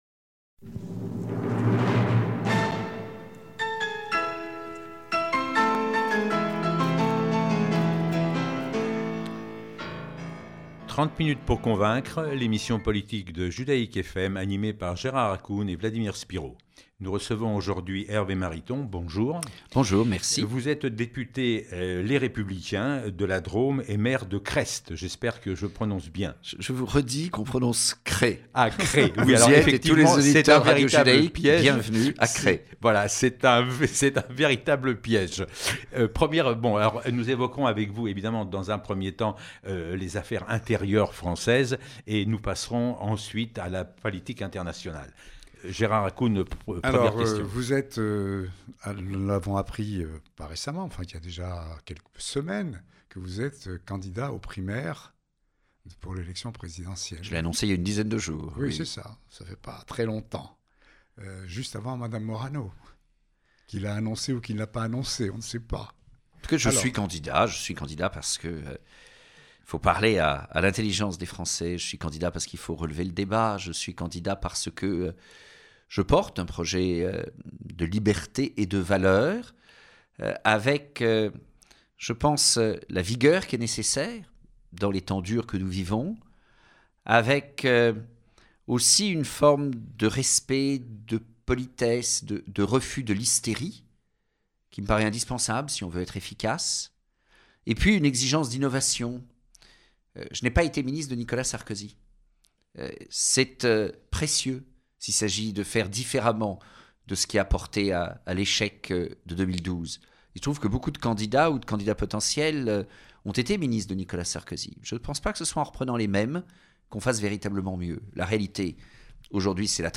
Ecouter ou réécouter l’interview de Hervé Mariton sur Judaïques FM dans l’émission 30 minutes pour convaincre.